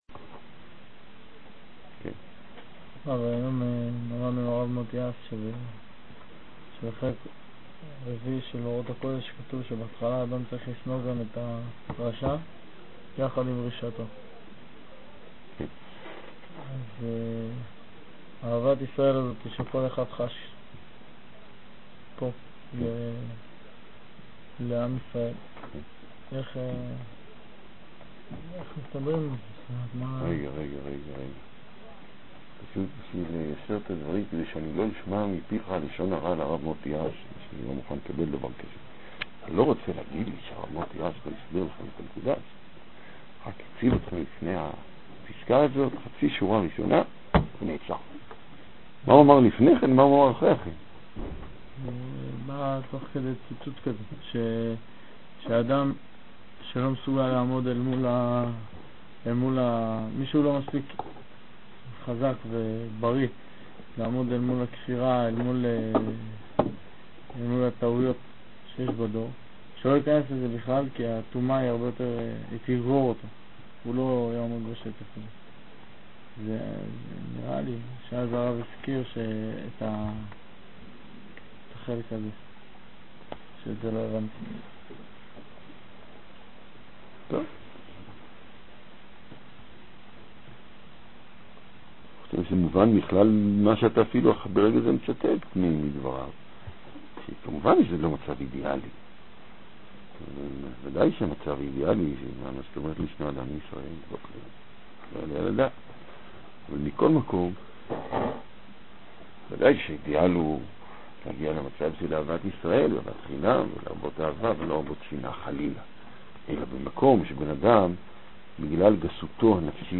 התורה אומרת כי צריך לשנוא את הרשעים כיצד זה מסתדר עם דרכנו? מתוך שו"ת. ניתן לשלוח שאלות בדוא"ל לרב